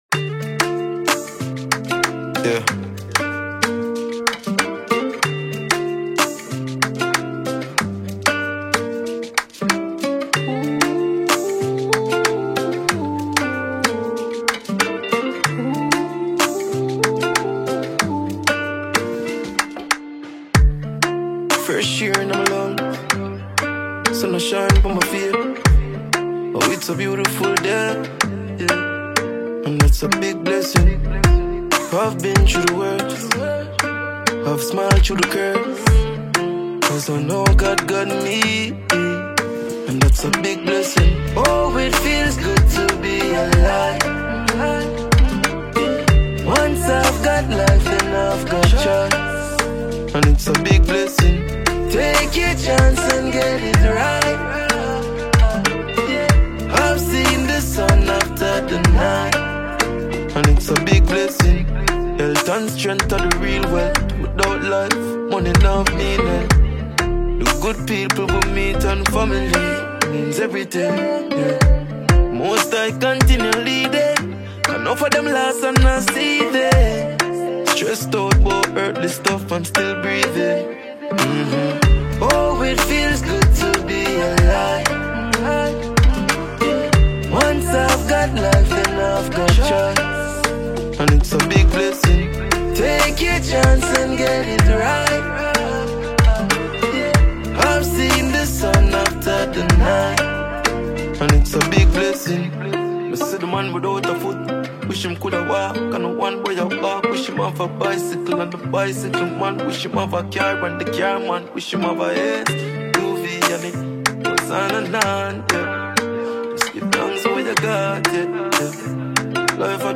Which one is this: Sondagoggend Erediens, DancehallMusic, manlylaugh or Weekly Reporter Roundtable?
DancehallMusic